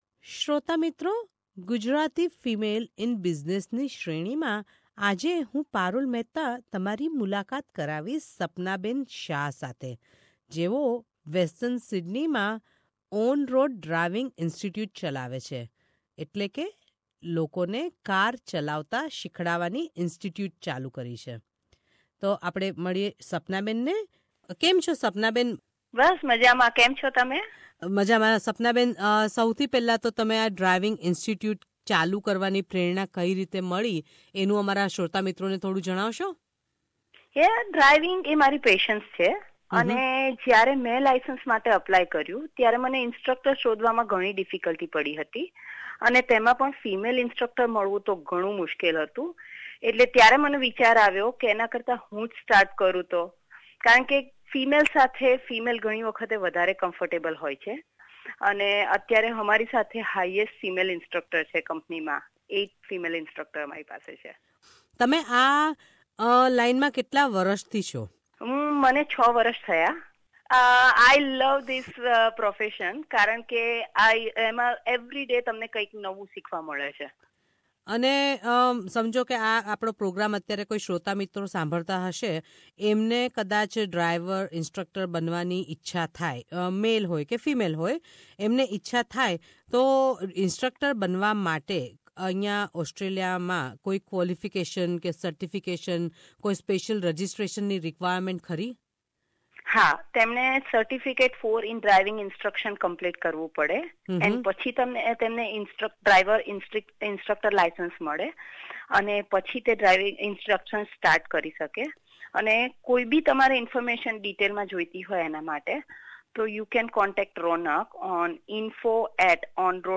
Gujaratis are known for their business acumen and skills. In this series we bring you conversations with Gujarati female entrepreneurs in Australia.